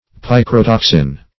Search Result for " picrotoxin" : The Collaborative International Dictionary of English v.0.48: Picrotoxin \Pic`ro*tox"in\, n. [Gr.
picrotoxin.mp3